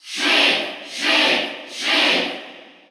Sheik_Cheer_Russian_SSBU.ogg